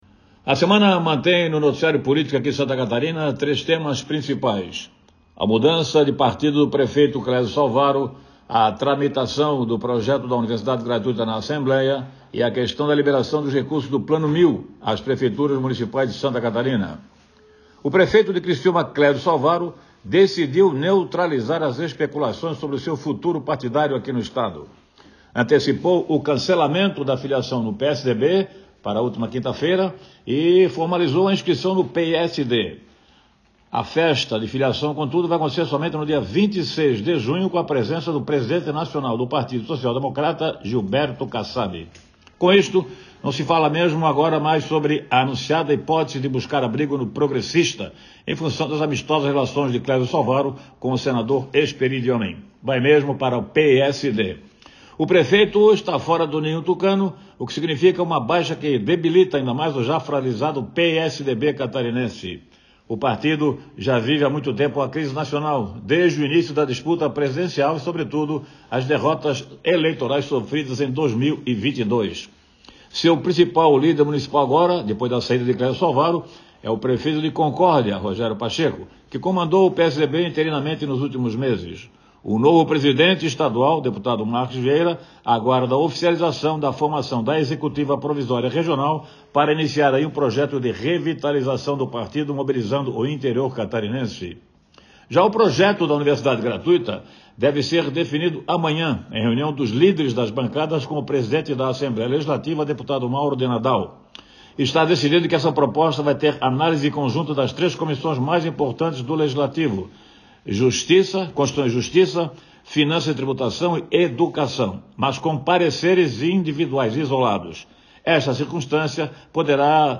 Jornalista comenta sobre a troca de partido do prefeito de Criciúma, Clésio Salvaro, a tramitação do projeto da Universidade Gratuita na Alesc e a liberação dos recursos do extinto Plano 1.000